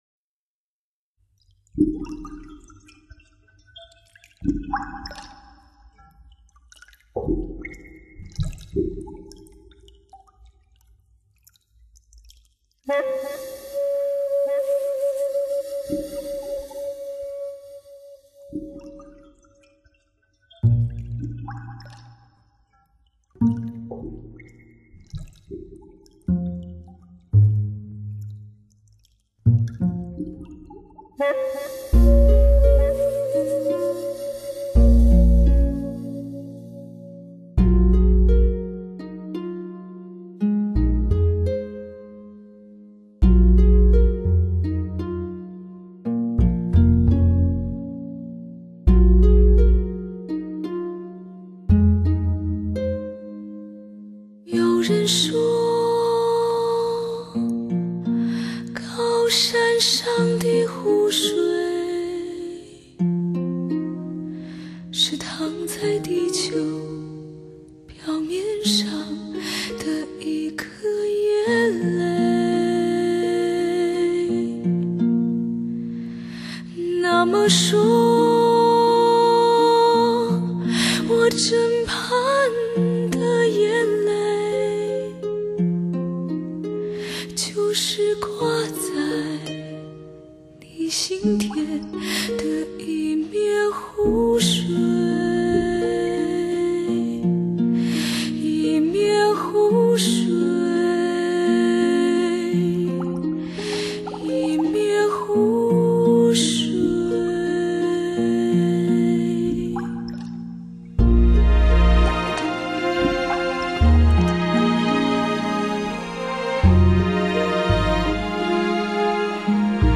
人声通透耳目一新的发烧天碟